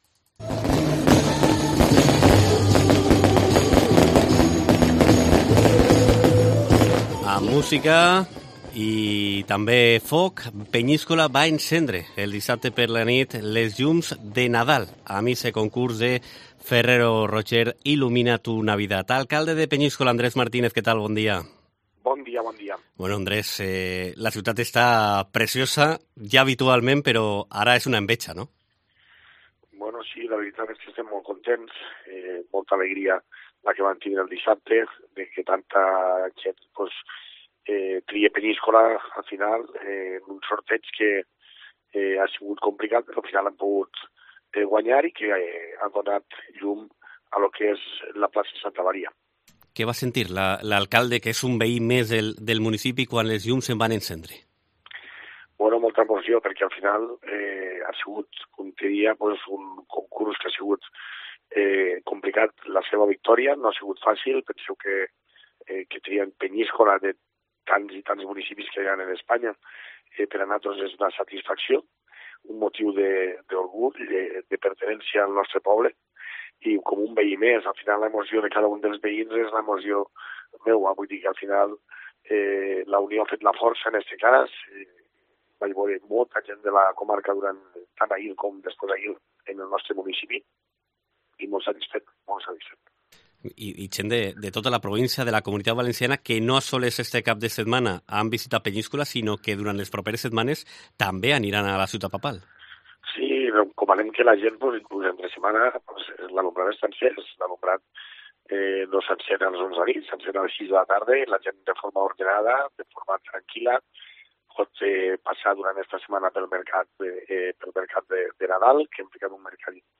Entrevista
Peñíscola brilla más que nunca esta Navidad y se plantea mantener el alumbrado después de Reyes, como explica a COPE el alcalde, Andrés Martínez